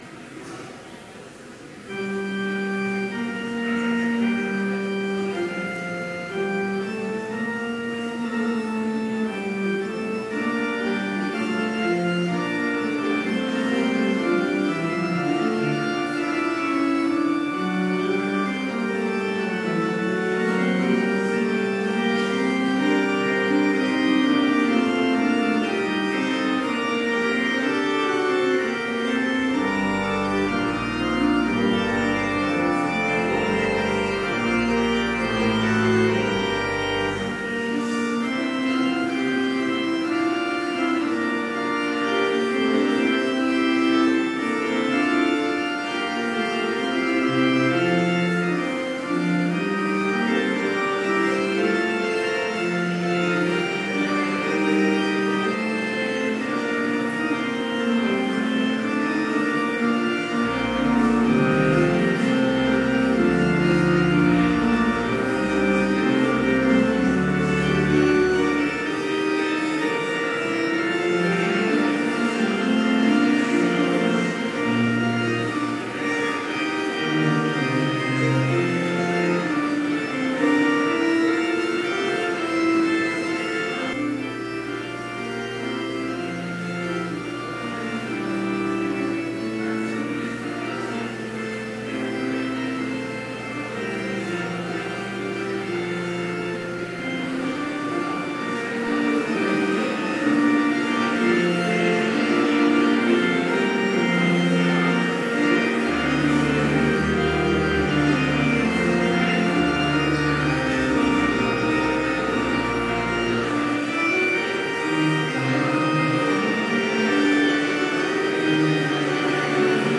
Complete service audio for Chapel - August 31, 2017